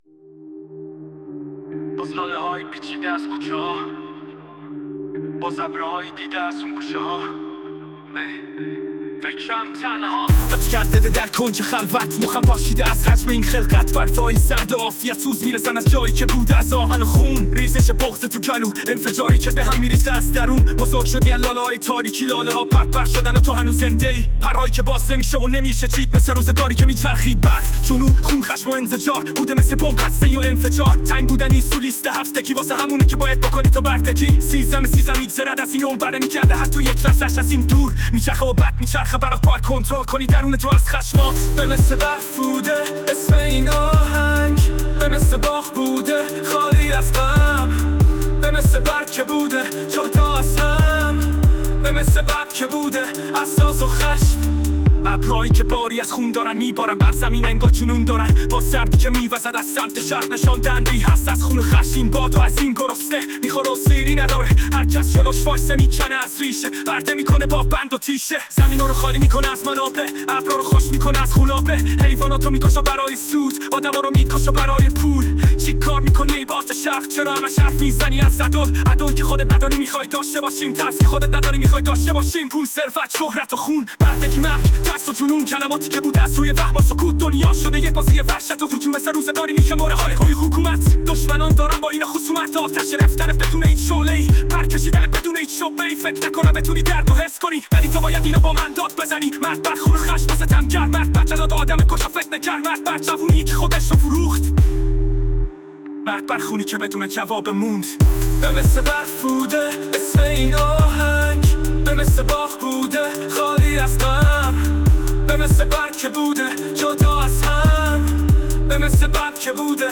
اهنگ ب مثل برف با هوش مصنوعی